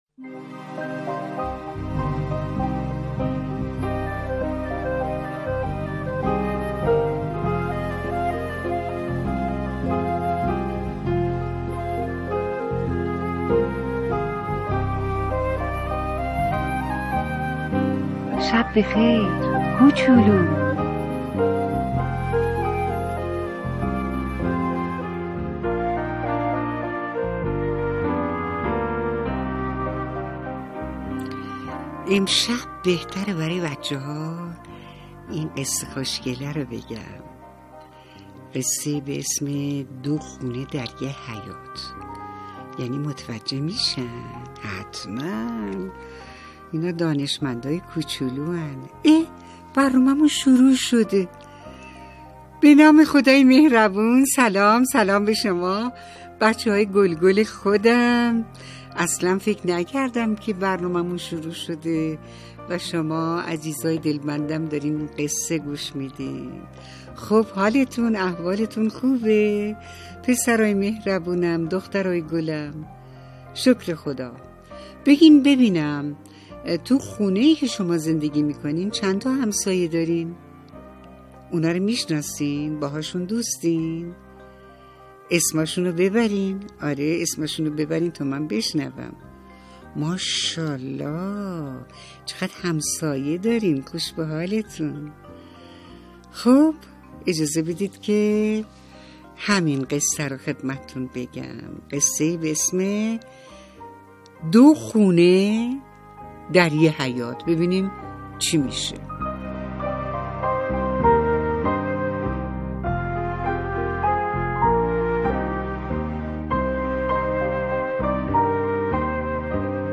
قصه صوتی کودکانه